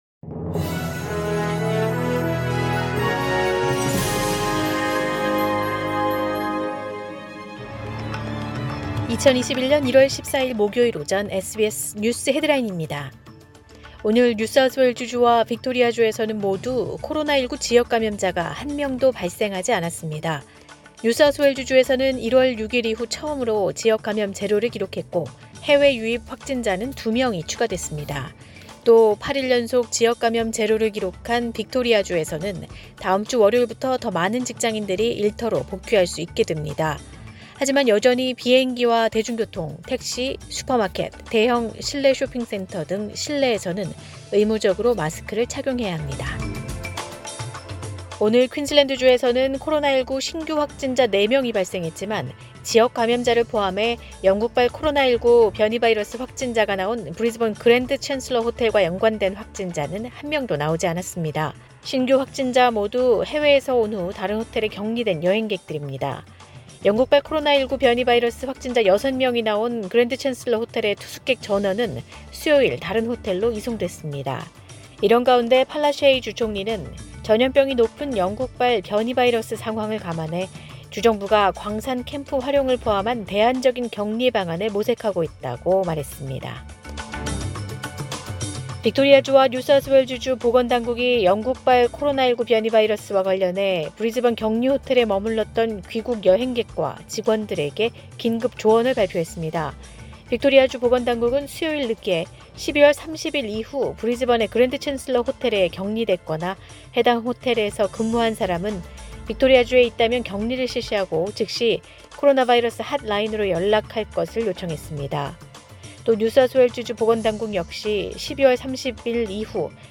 2021년 1월 14일 목요일 오전의 SBS 뉴스 헤드라인입니다.